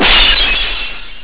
glassbreaking.wav